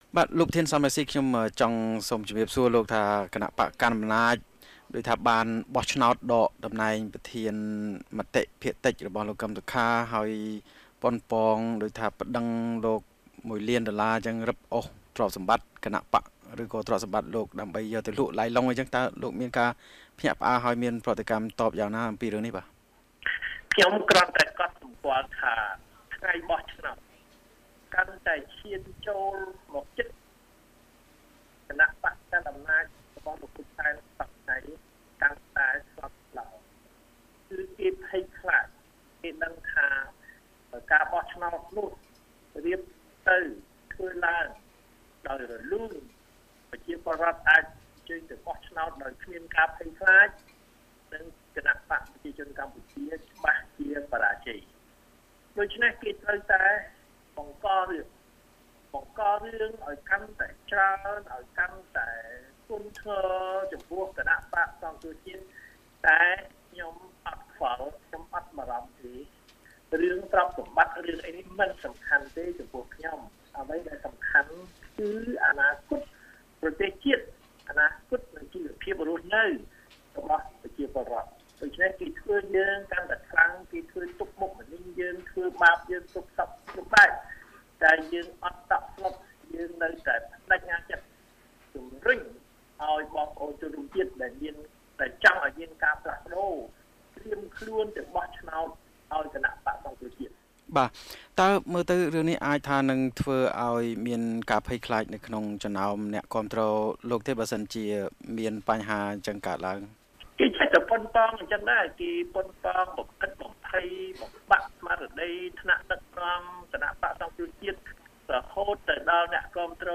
បទសម្ភាសន៍ VOA៖ លោក សម រង្ស៊ីថា មិនតក់ស្លុតនឹងការគំរាមរឹបអូសទ្រព្យសម្បត្តិពីសំណាក់លោក ហ៊ុន សែន